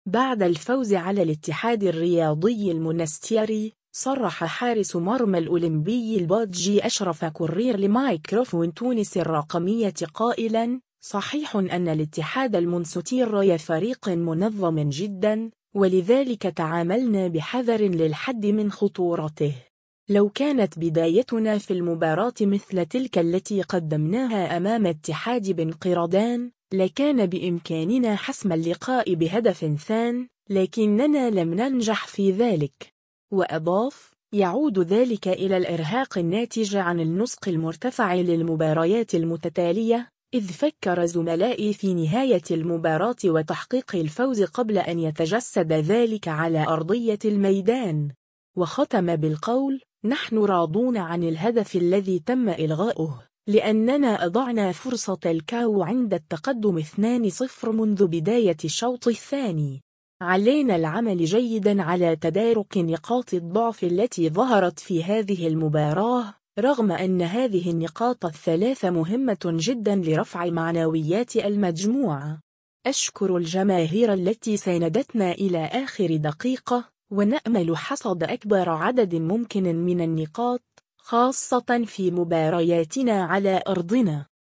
الرابطة المحترفة الأولى: الأولمبي الباجي – الاتحاد المنستيري، تصريح